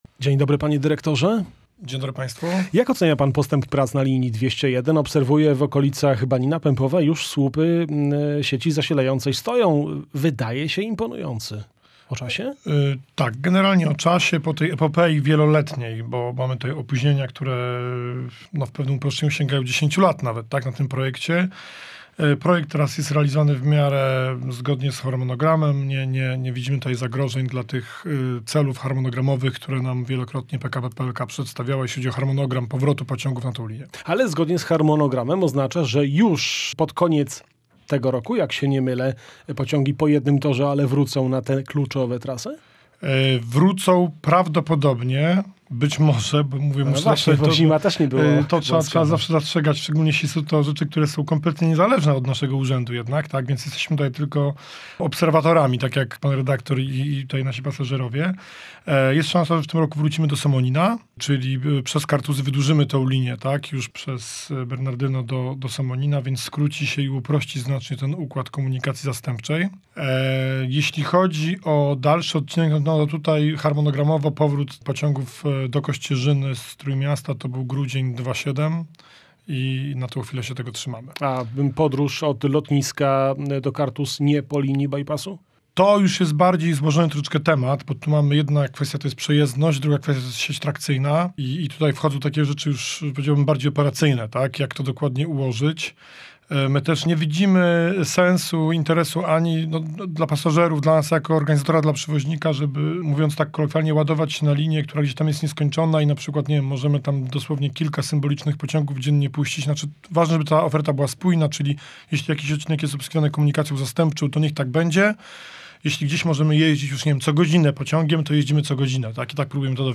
Jak mówił w rozmowie z Radiem Gdańsk